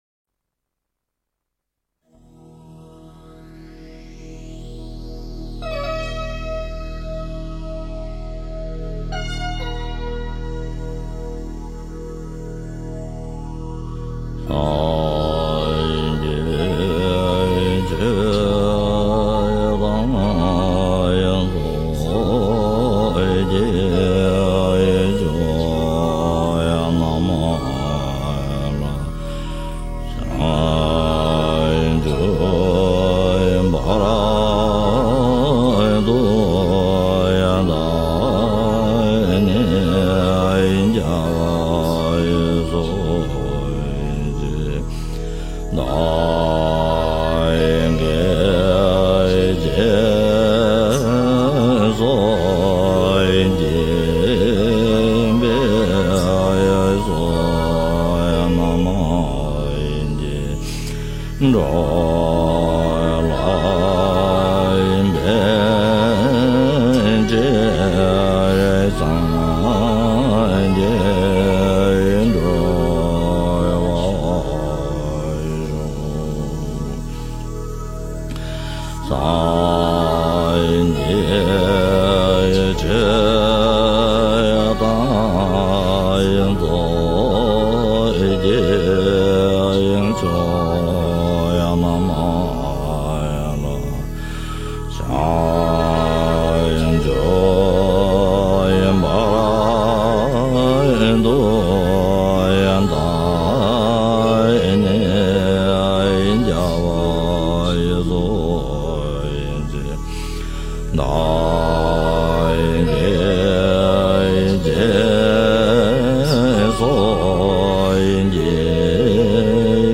佛音 诵经 佛教音乐 返回列表 上一篇： 般若波罗蜜多心经-